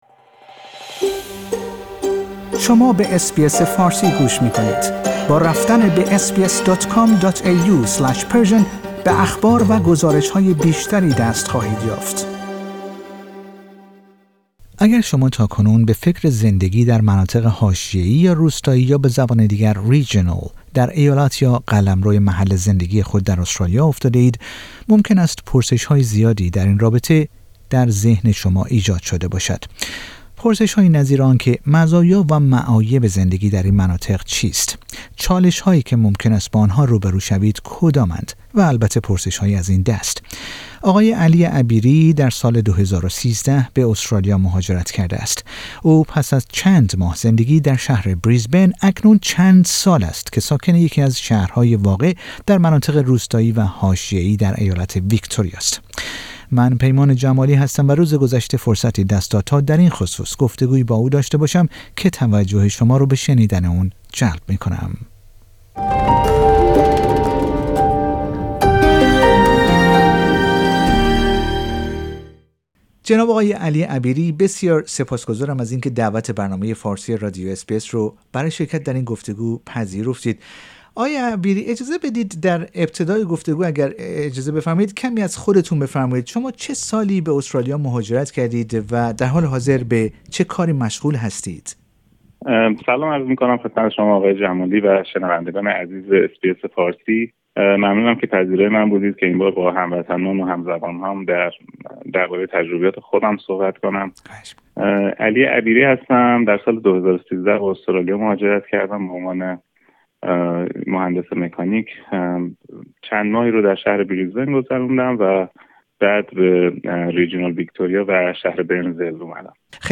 گفتگو با رادیو اس بی اس فارسی